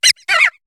Cri de Ratentif dans Pokémon HOME.